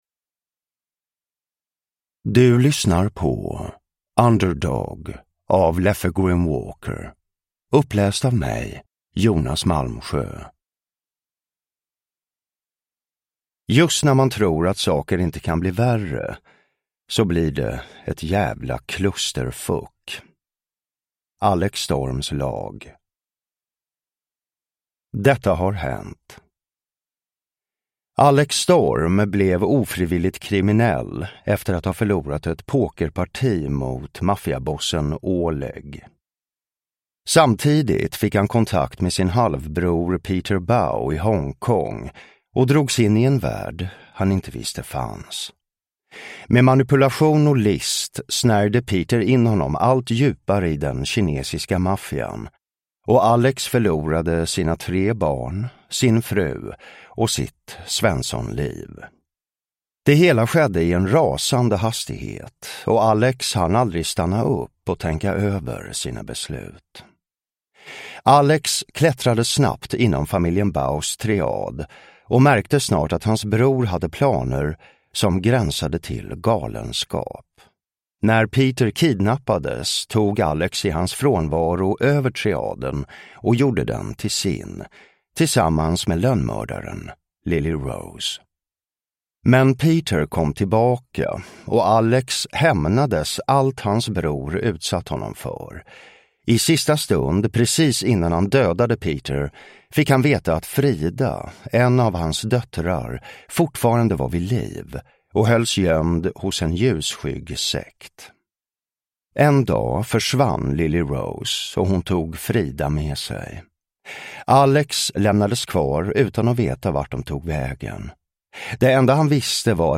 Uppläsare: Jonas Malmsjö
Ljudbok